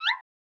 Click Back (6).wav